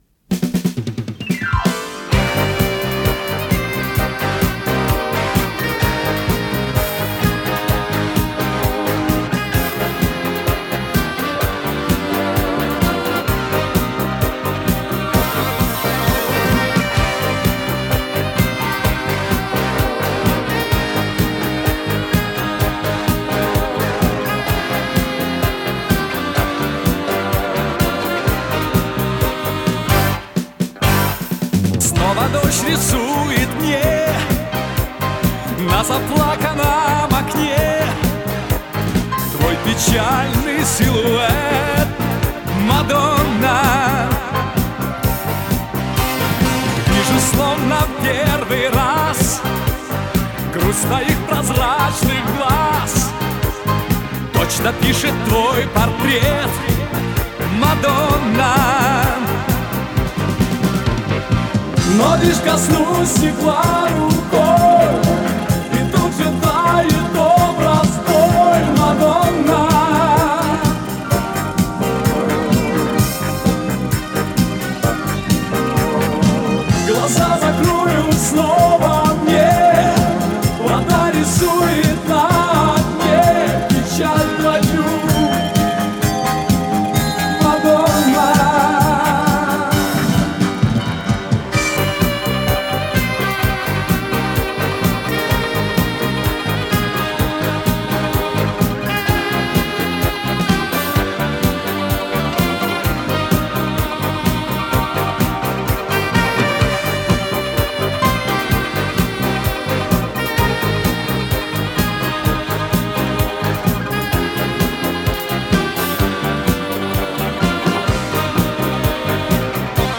С пластинки